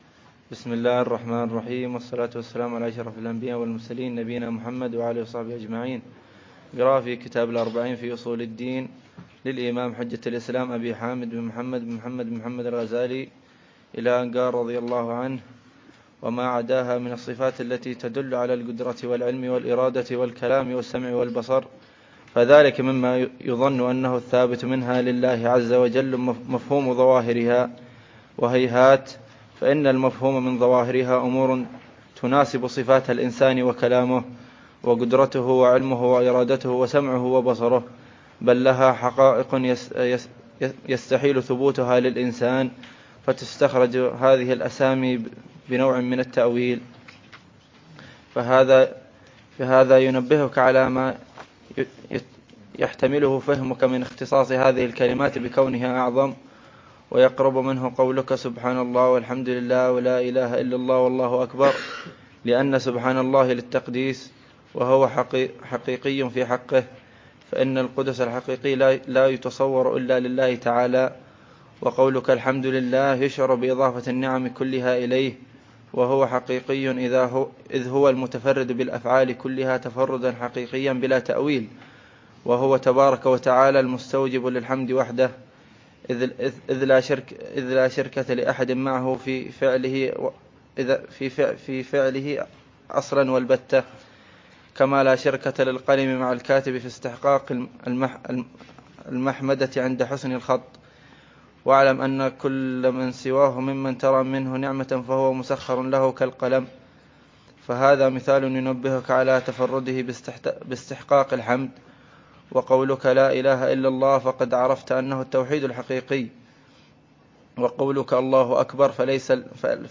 الدرس الثاني عشر للعلامة الحبيب عمر بن محمد بن حفيظ في شرح كتاب: الأربعين في أصول الدين، للإمام الغزالي .